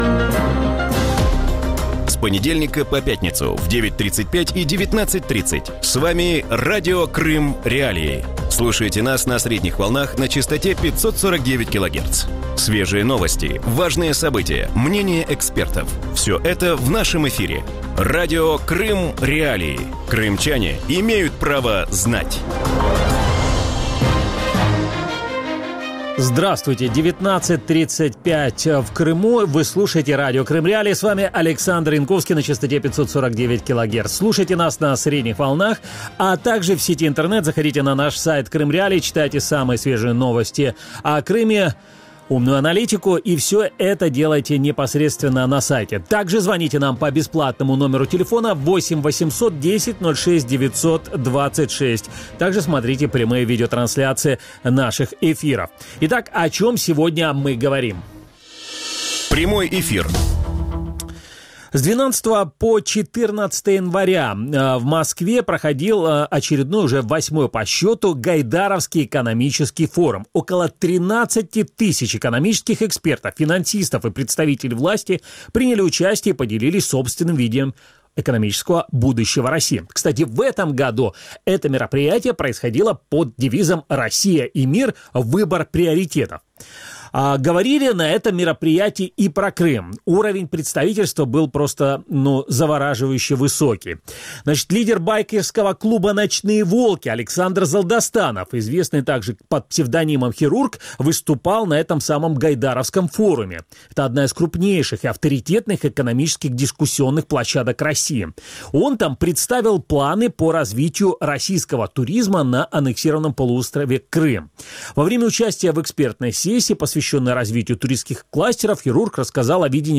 У вечірньому ефірі Радіо Крим.Реалії говорять про підсумки економічного Гайдарівського форуму і перспективи російської економіки. Як бачать економічну ситуацію в Росії провідні економісти, чи є у Росії шанси на економічний розвиток і чи чують у Кремлі ліберальну громадськість?